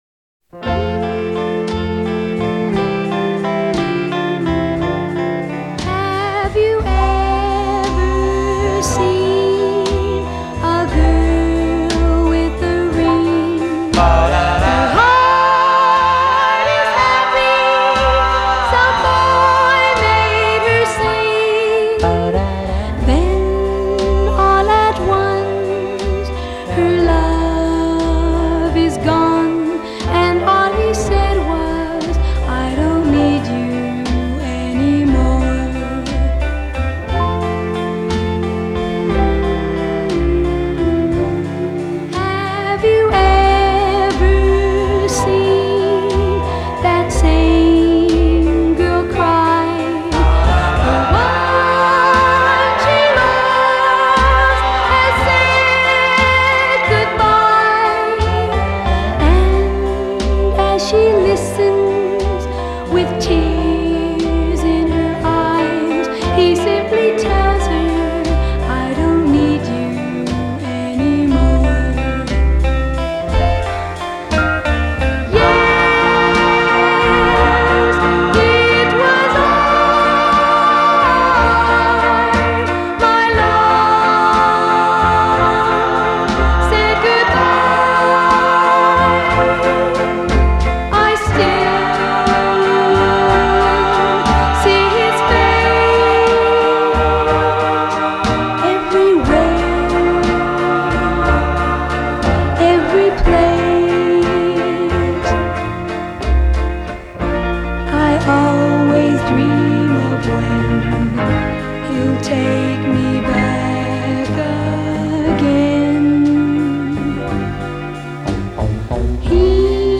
Жанр: Pop, Oldies, Doo-Woop
lead vocals